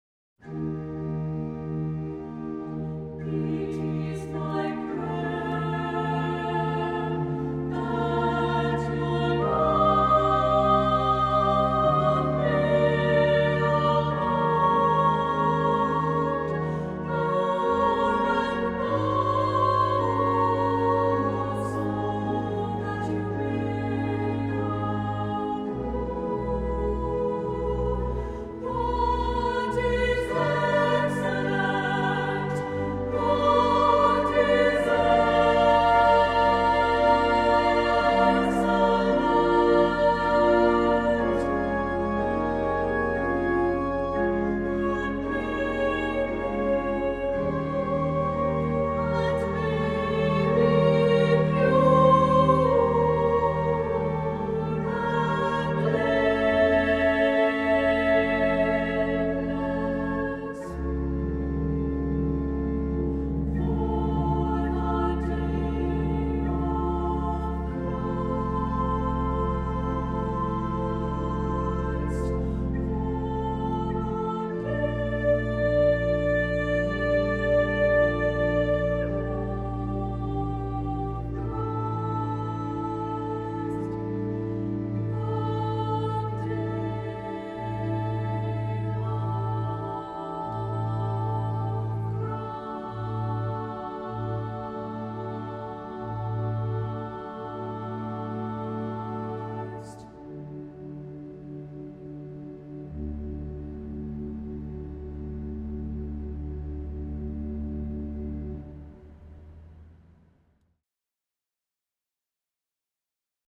Voicing: SA; SSA; Three-part equal